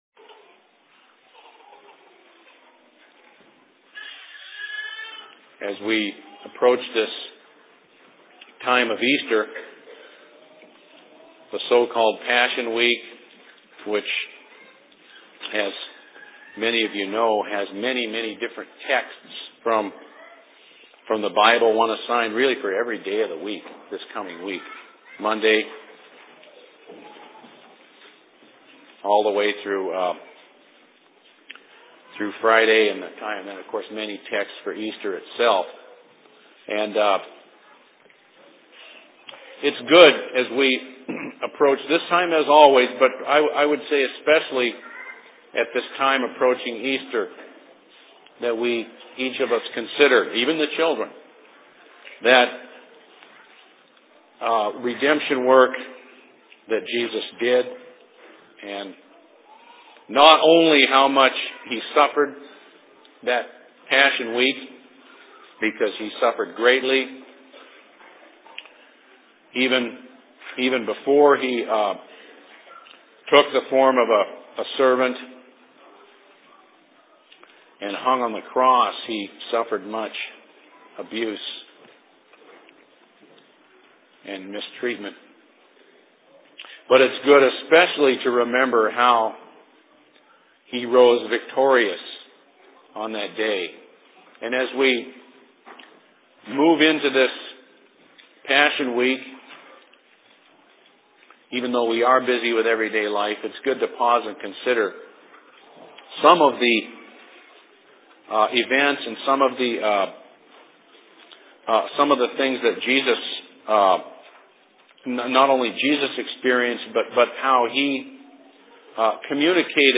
Sermon in Seattle 16.03.2008
Location: LLC Seattle